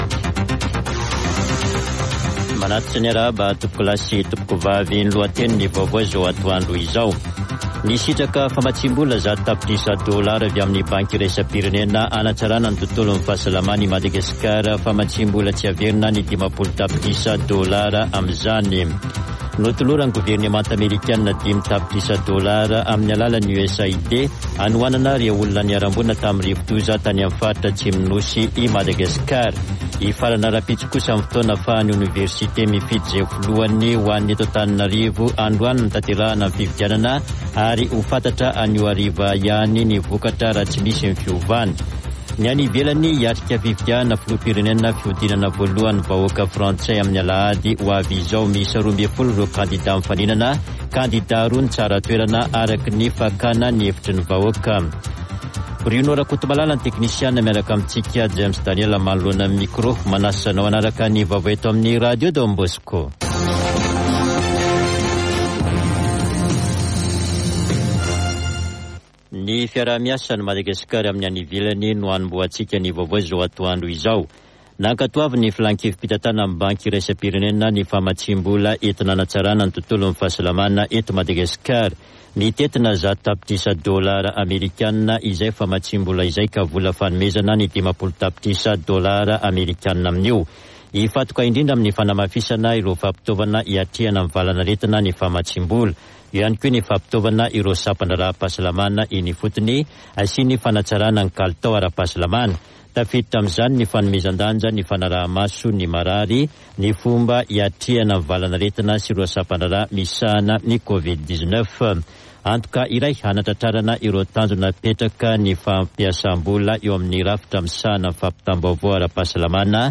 [Vaovao antoandro] Alakamisy 07 avrily 2022